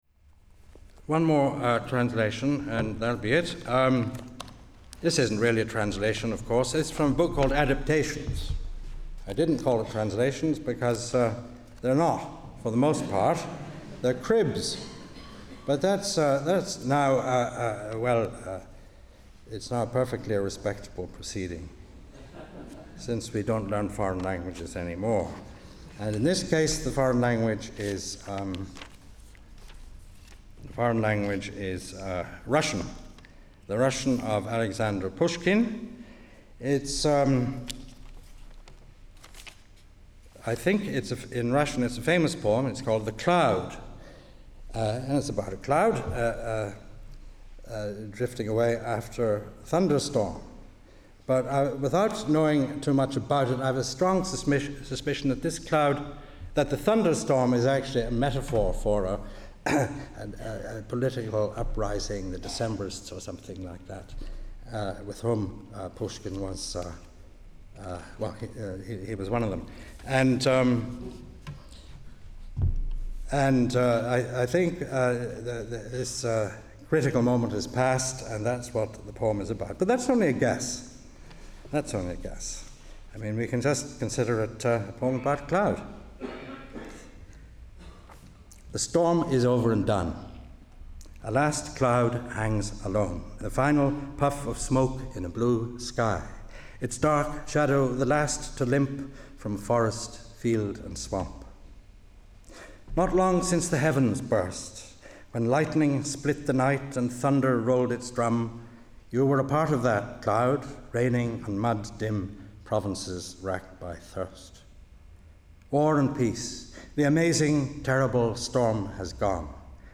The following recordings were made at the Louis MacNeice Centenary Celebration and Conference at Queen's University, Belfast in September 2007.